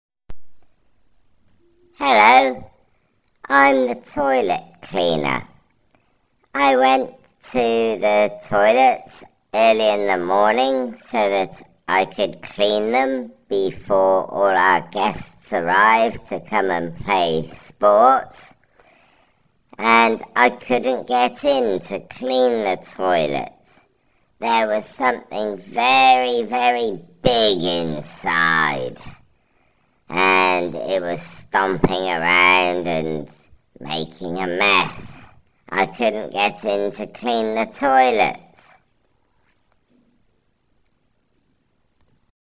Listen to the cleaner
sport-centre-cleaner.mp3